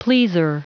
Prononciation du mot pleaser en anglais (fichier audio)
Prononciation du mot : pleaser